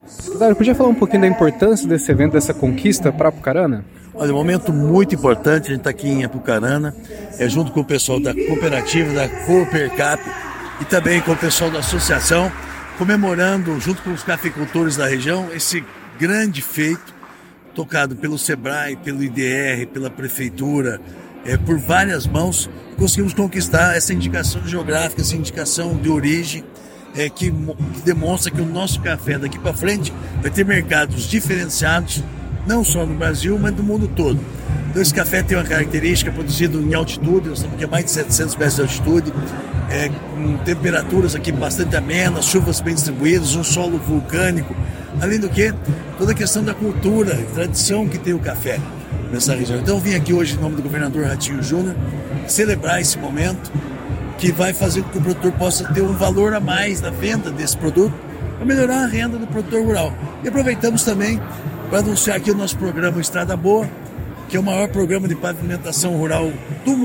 Márcio Nunes – Secretário Estadual da Agricultura e do Abastecimento